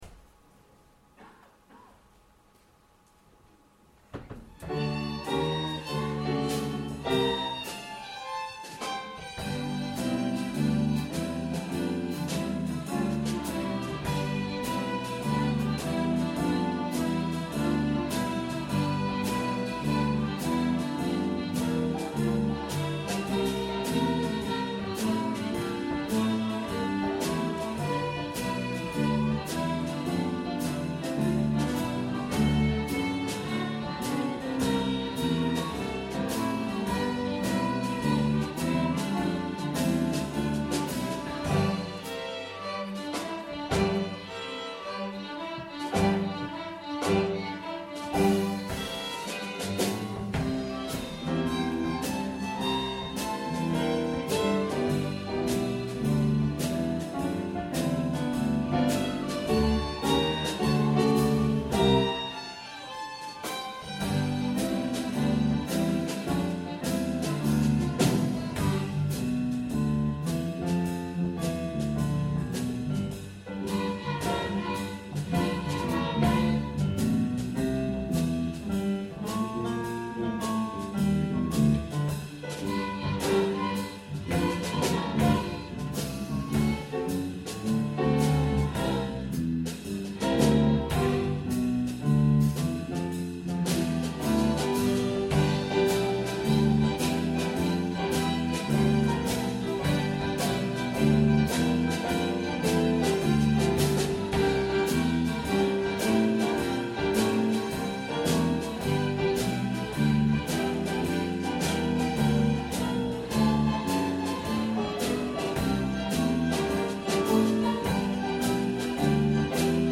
String Ensemble Blues
Summer Concert 2014